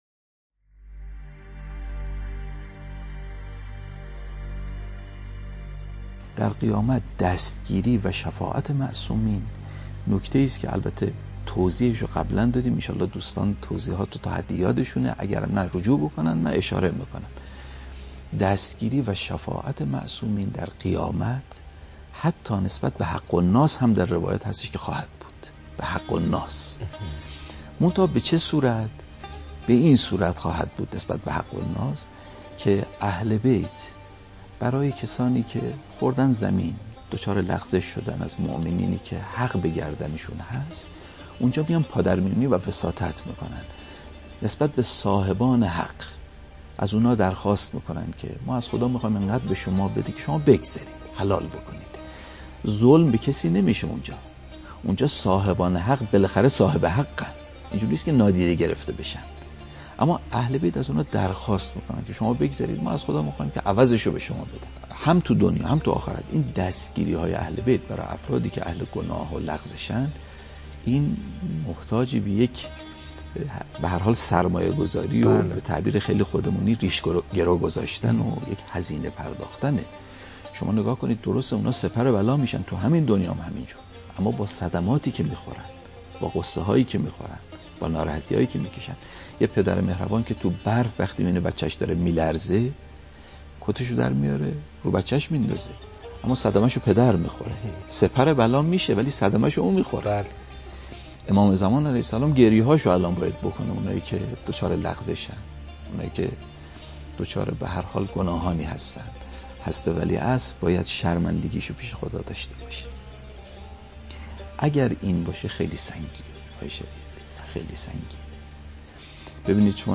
در یکی از سخنرانی‌های خود